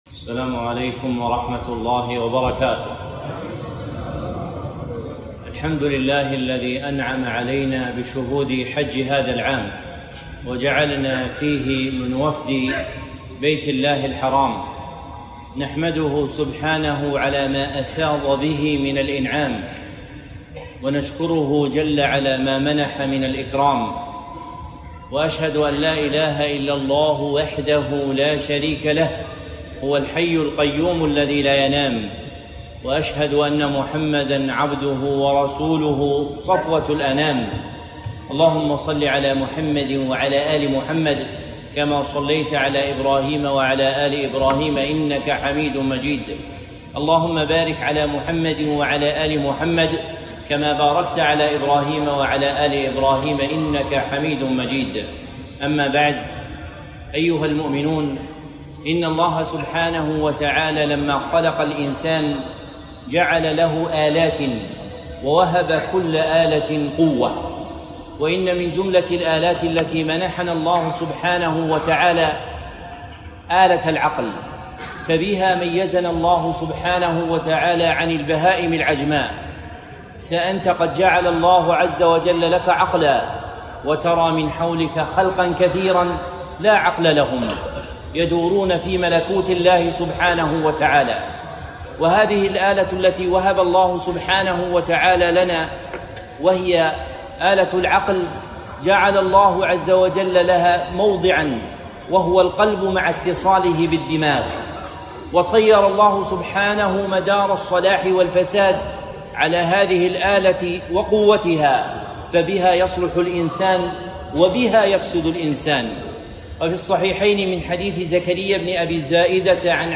محاضرة - حال المسلم بعد الحج